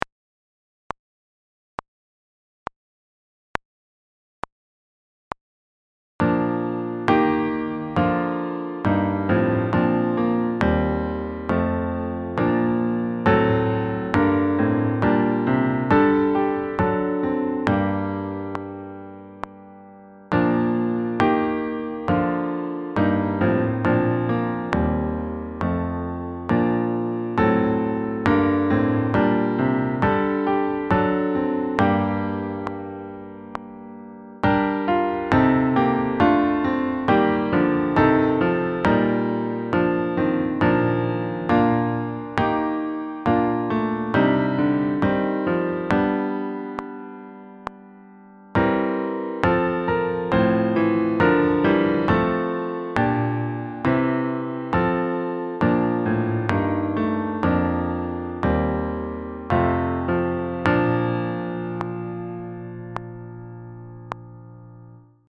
5. Each TRACK start with one measure of clicks to set the tempo and continue throughout the track
Chorale No. 2 (Bach) Four Part (w metronome 68 bpm)
Updated June 29 – An additional measure has been added to the click track and an emphasis has been added to the first beat of each measure.